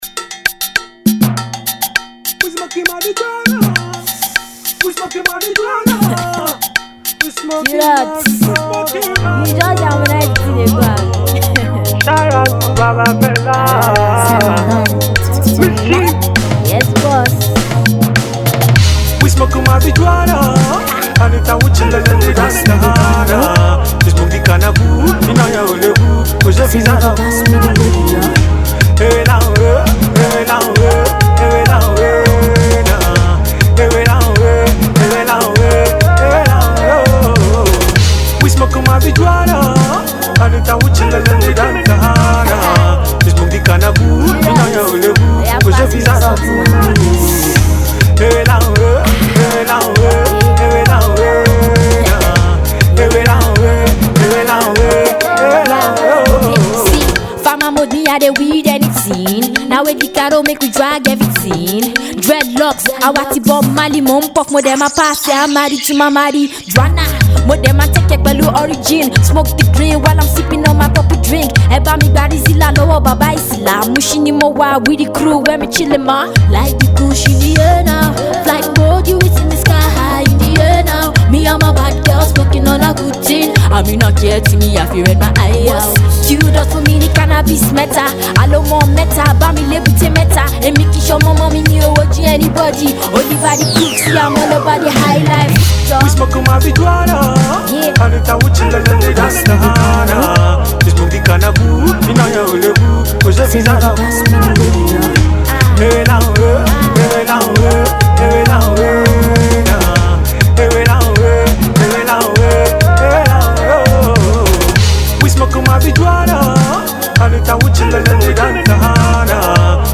Alternative Rap
Street Rap